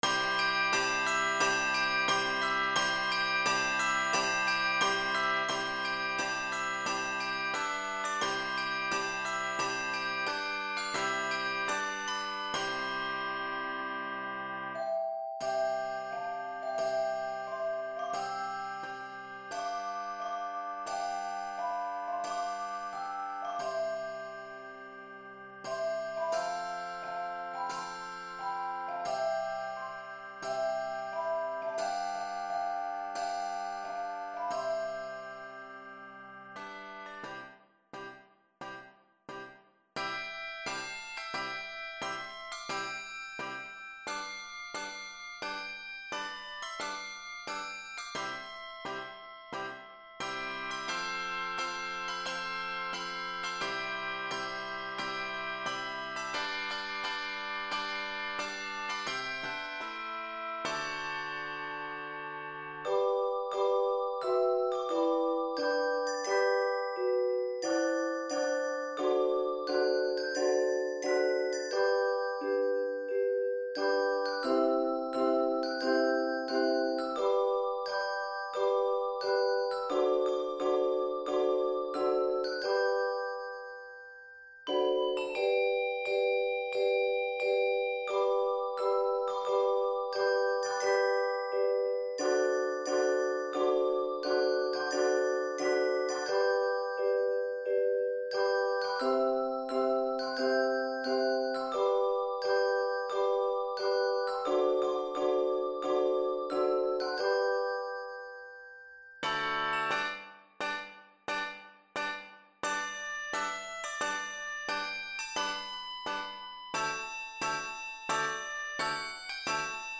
It is scored in C Major and G Major.